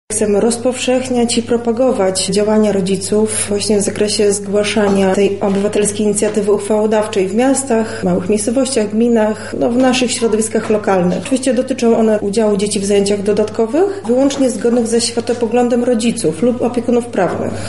Konferencja Porozumienie 2